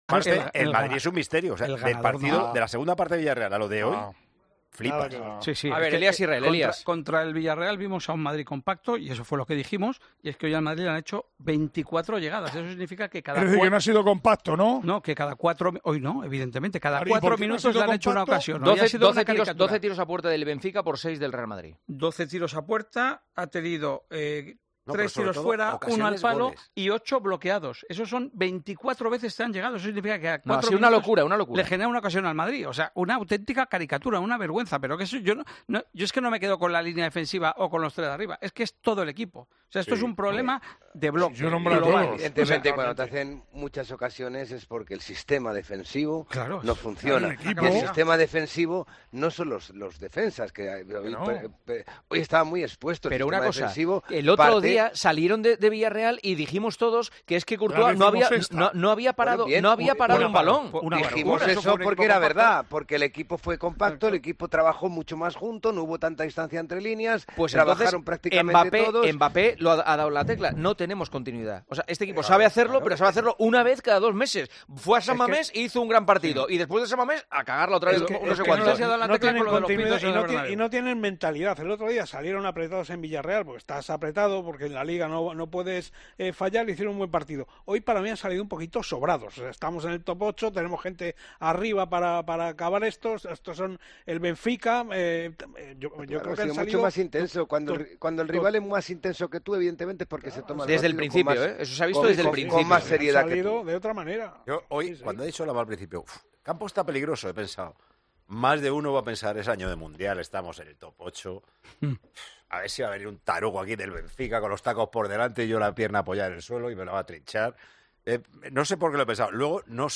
Juanma Castaño y los tertulianos de El Partidazo de COPE analizan la debacle del Real Madrid ante el Benfica